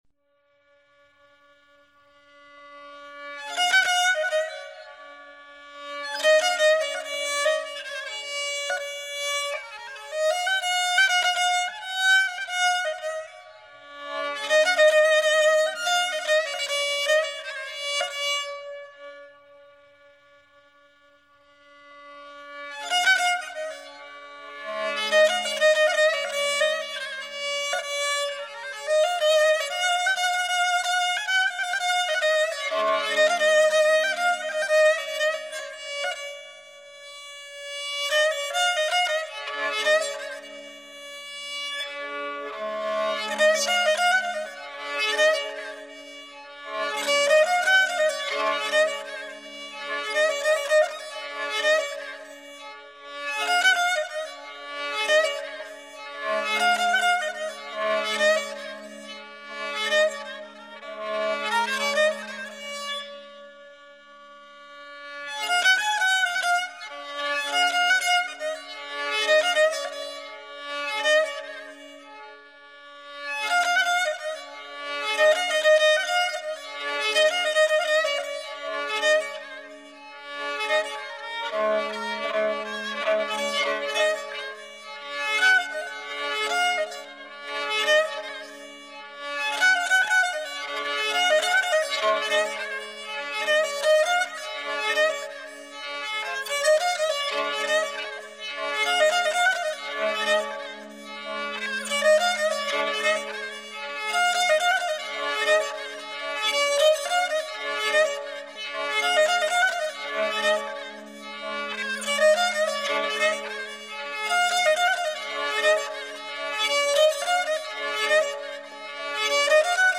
Improvisation